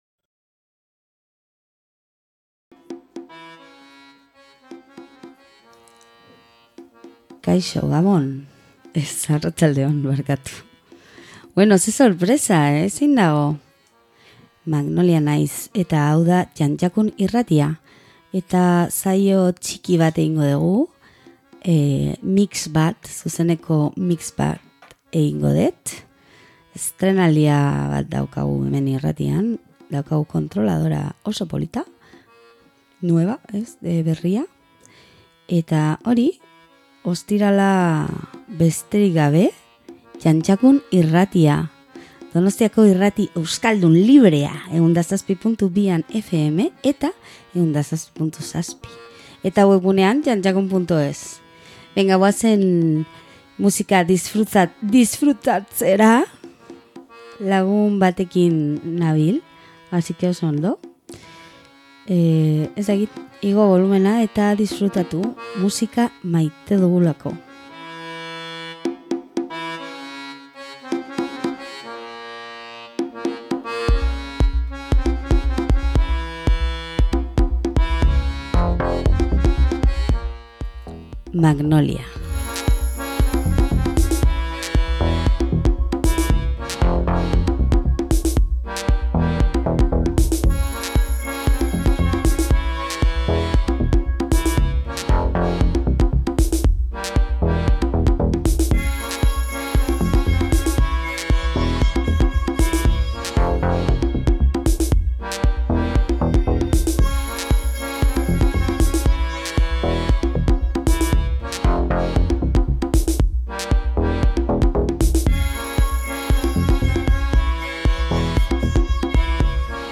ZUZENEKO MIX BAT